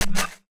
UIClick_Next Button 01.wav